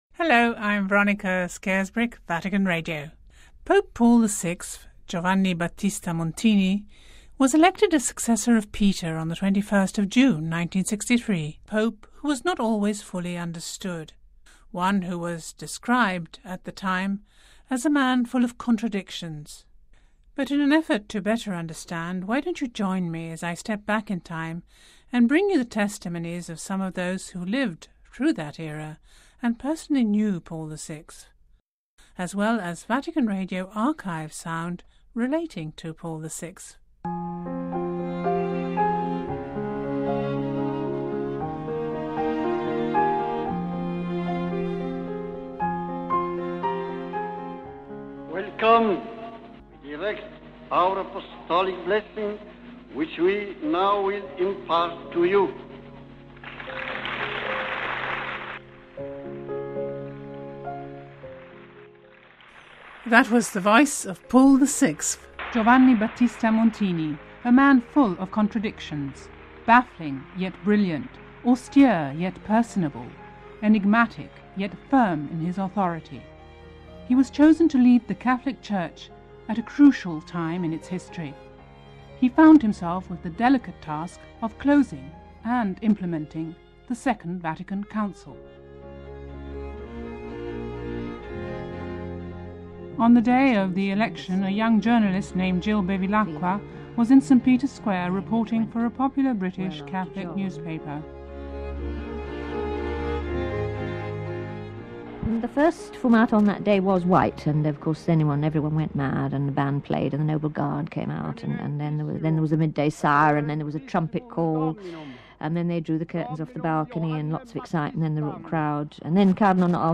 as she steps back in time to bring you the testimonies of some of those who lived through that era and personally knew Paul VI, as well as Vatican Radio archive sound...